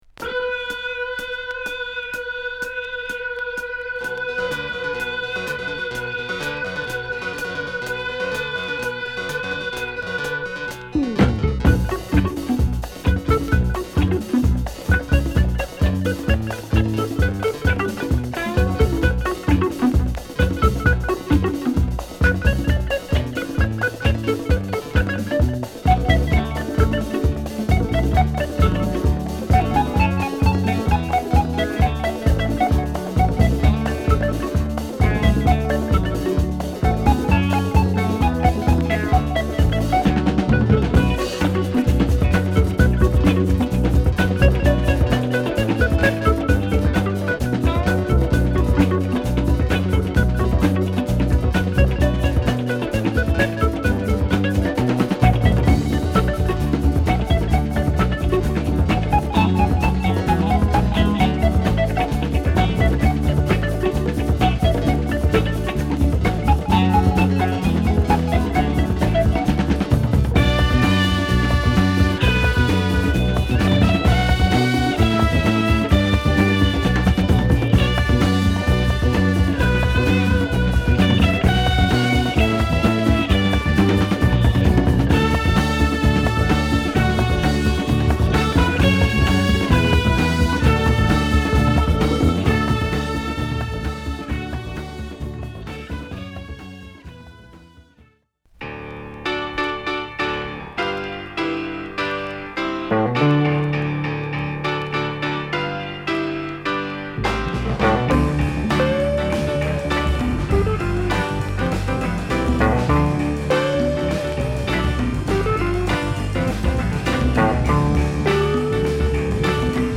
後半のファンキーな展開もカッコ良いナイスカヴァーです！
このB面の曲もホッコリユルくて良いです！